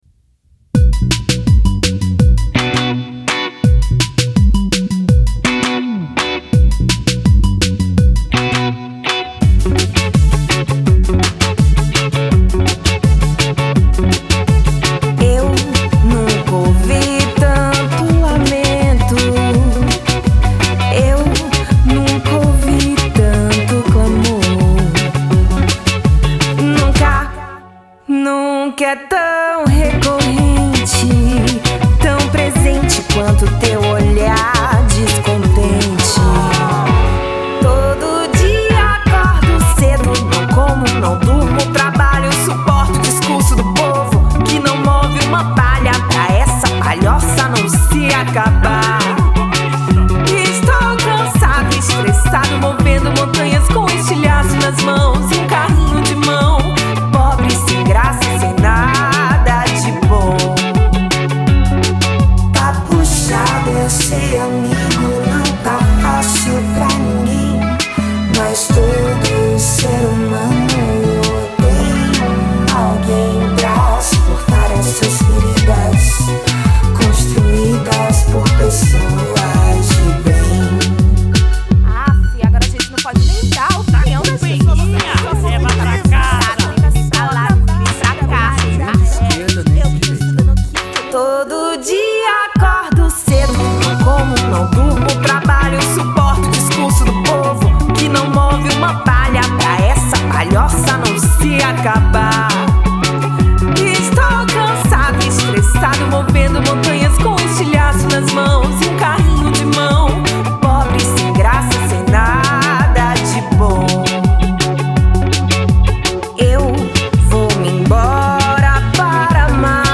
EstiloIndie